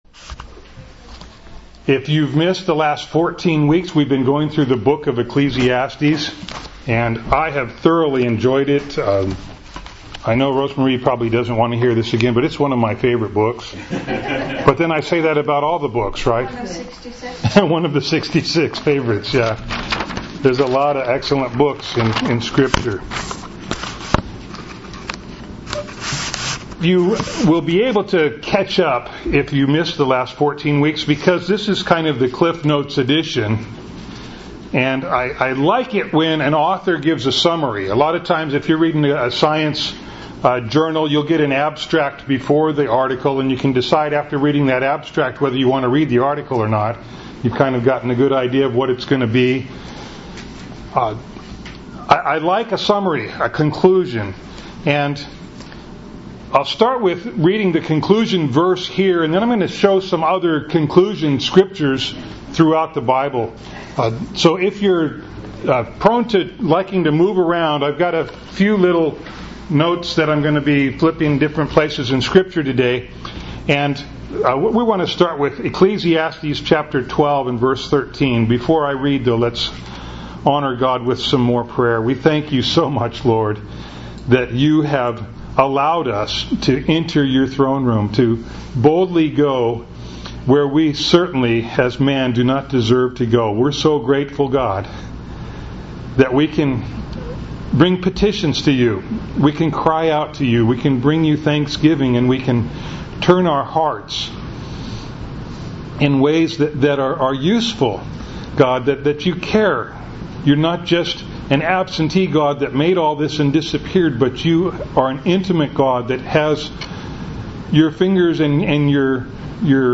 Ecclesiastes 12:9-14 Service Type: Sunday Morning Bible Text